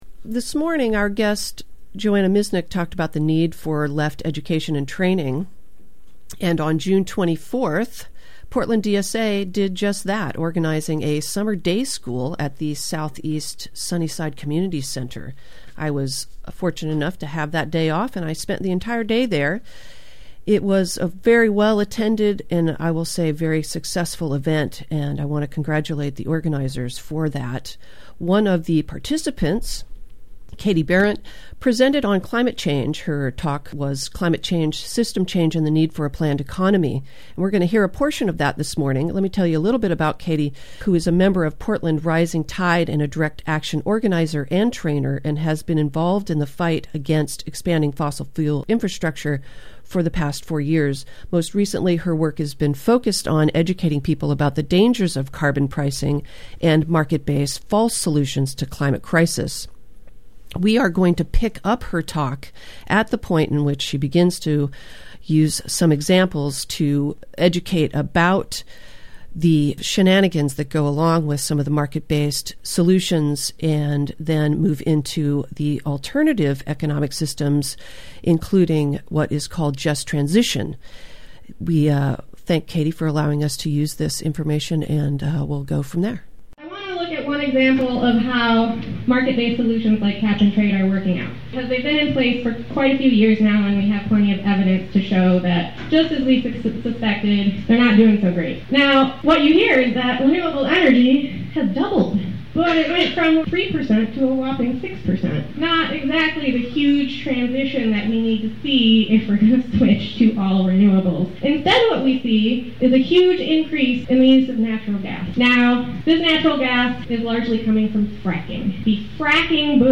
DSA SPEAKER ON WHY THE CLIMATE CRISIS REQUIRES SOCIALISM